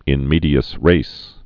(ĭn mēdē-əs rās)